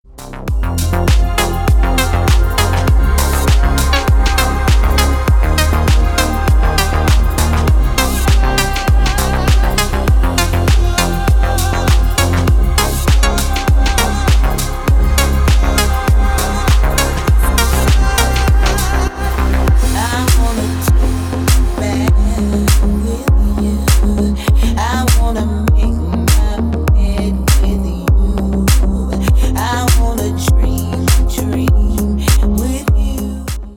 Deep House отбивка на смартфон